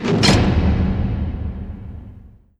Jail Metal Door - Berlin Atmospheres 0307.wav